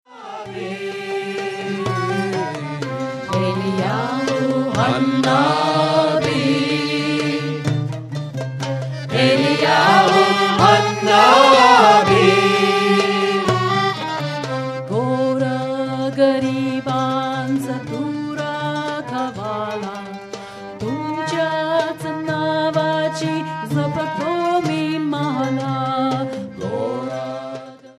Live in India! CD